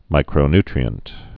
(mīkrō-ntrē-ənt, -ny-)